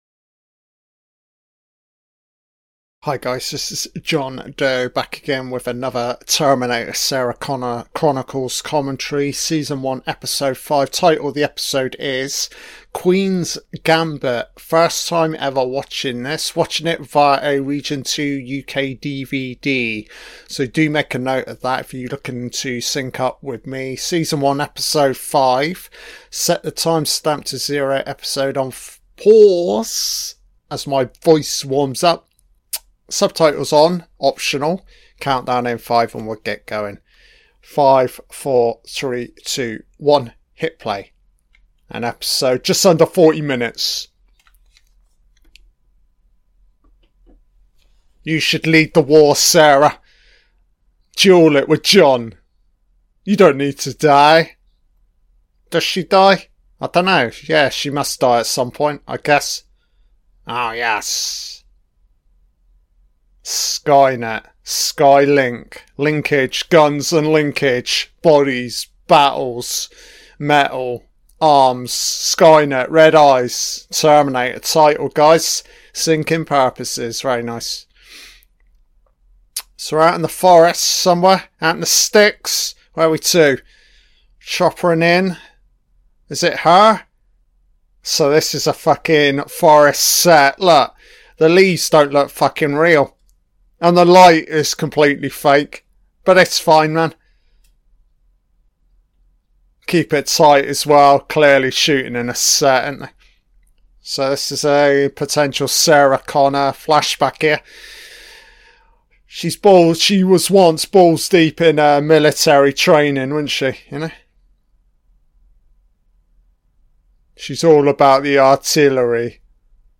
An Audio-Only Commentary on the 2008 TV Series The Sarah Connor Chronicles